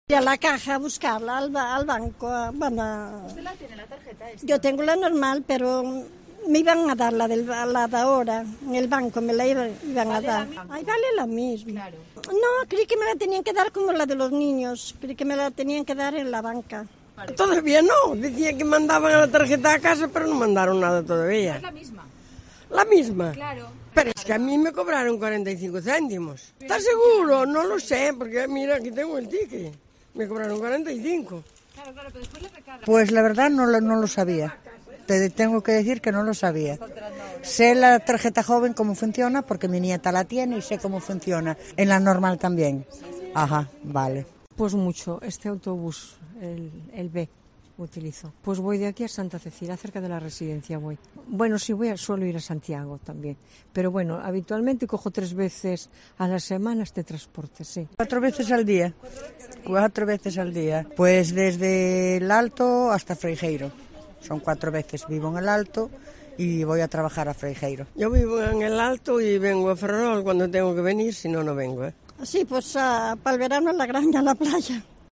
Mayores de Galicia hablan sobre la Tarjeta de Movilidad de Galicia